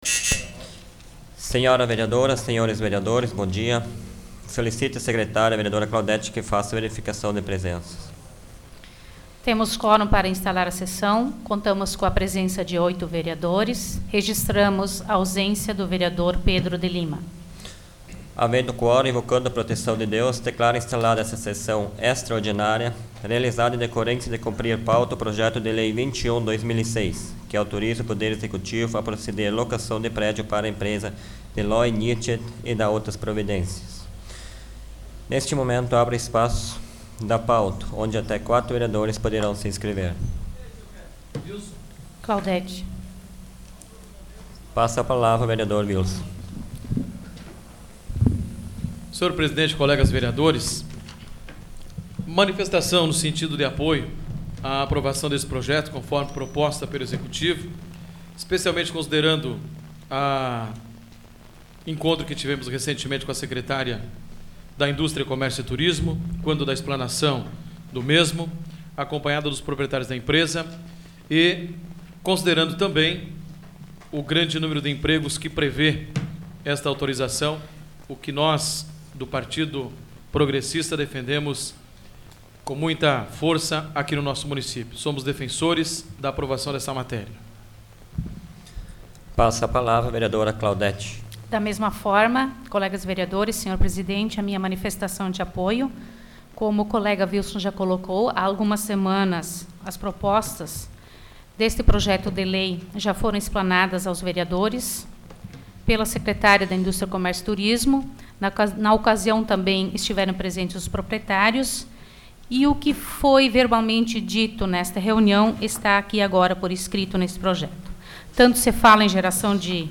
Áudio da 24ª Sessão Plenária Extraordinária da 12ª Legislatura, de 24 de julho de 2006